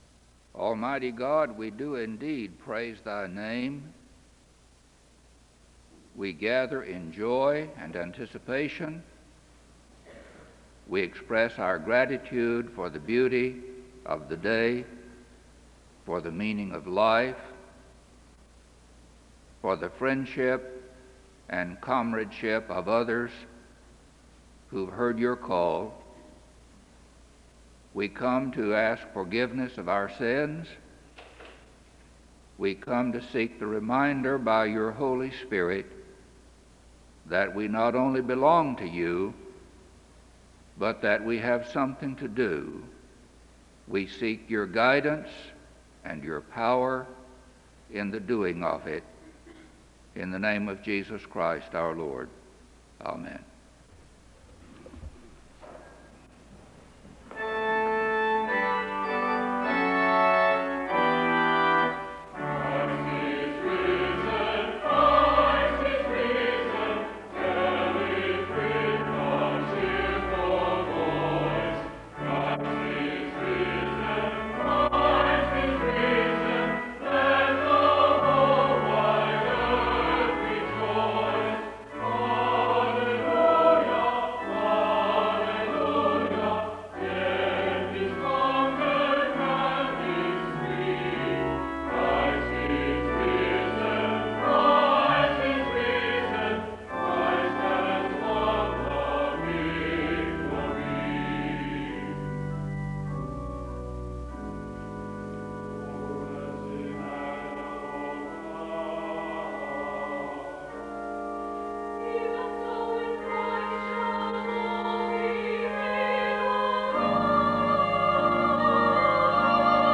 Casa SEBTS Missionary Day...